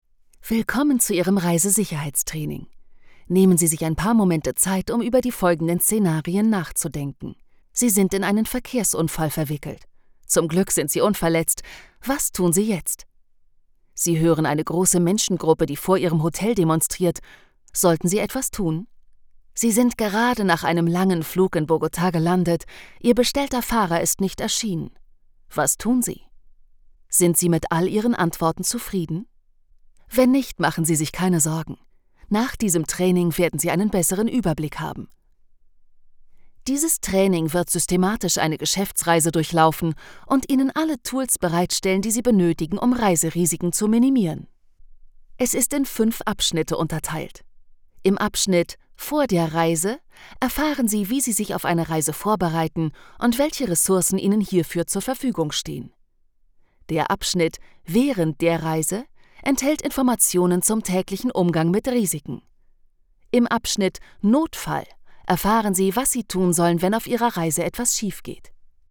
Mein Studio besteht aus einer Desone Box, einem Neumann TLM 103, einem RME Fireface UCX II Interface, Genelec 1029 A Monitoren, Logic Pro X, Reaper, MacBook Pro, MacMini.
Weiches, warmes Timbre, klar, seriĂ¶s. Mezzosopran
Sprechprobe: eLearning (Muttersprache):
I am a native German speaker and my English is conversational.